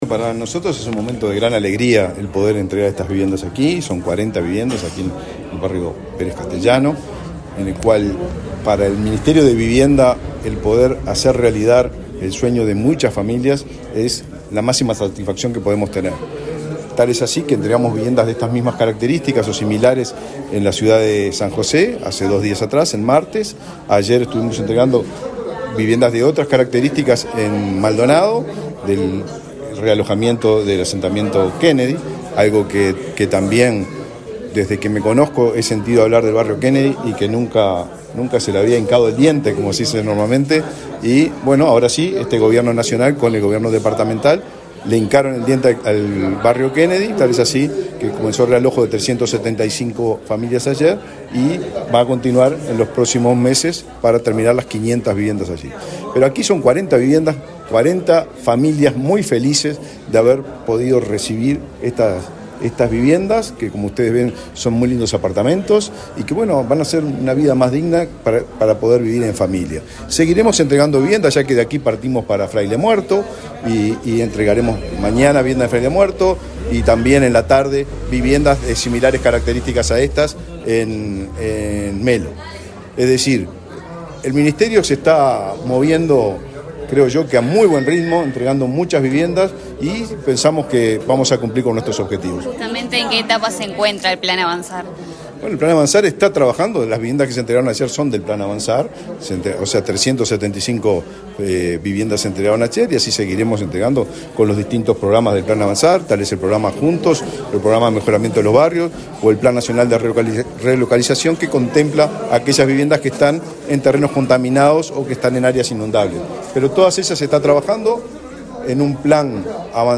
Declaraciones a la prensa del ministro de Vivienda, Raúl Lozano
El ministro de Vivienda, Raúl Lozano, dialogó con la prensa, luego de participar en la entrega de 40 viviendas de un edificio sito en Olmedo y Arrotea